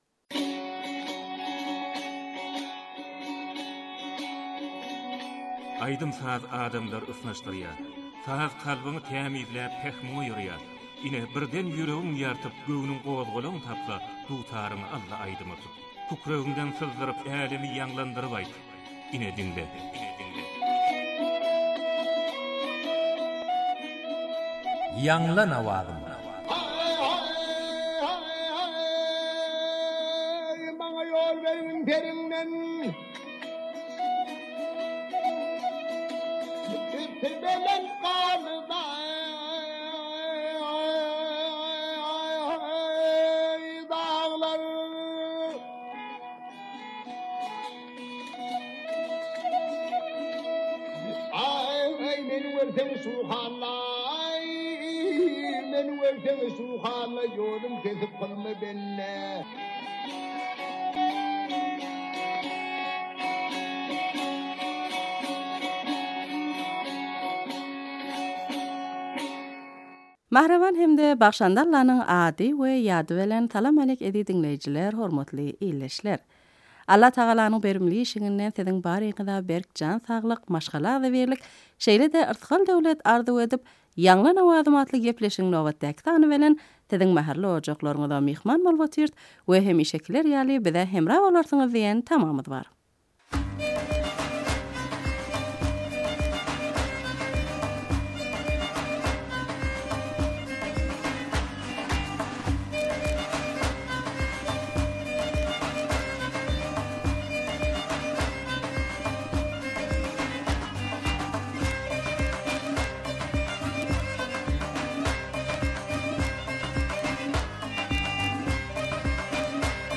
turkmen owaz aýdym